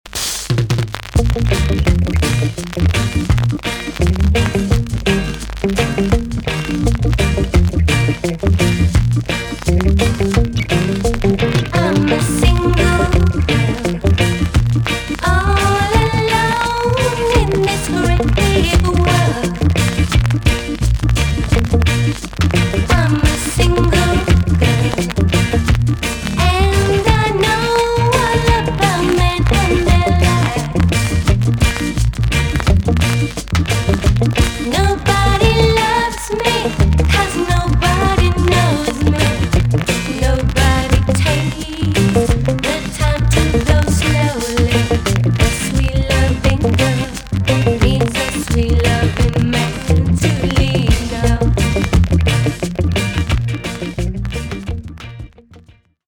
TOP >REGGAE & ROOTS
VG ok 全体的に軽いチリノイズが入ります。
UK , NICE LOVERS ROCK TUNE!!